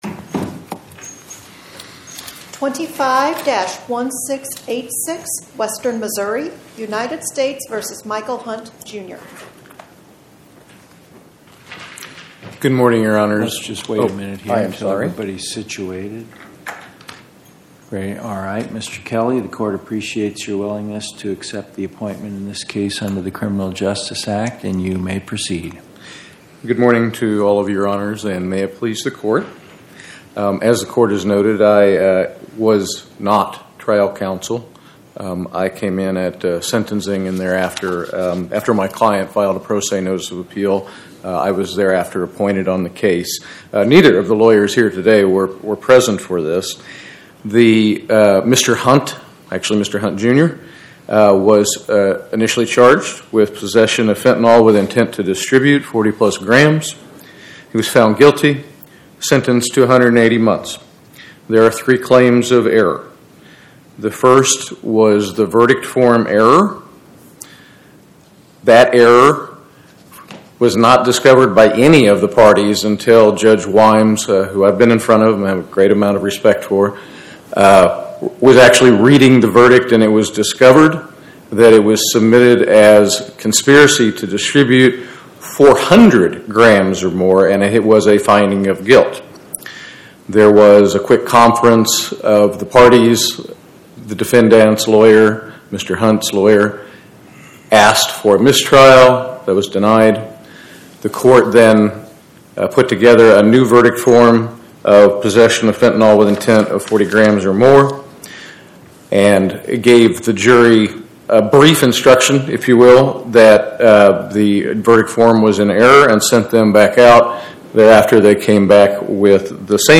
Oral argument argued before the Eighth Circuit U.S. Court of Appeals on or about 11/21/2025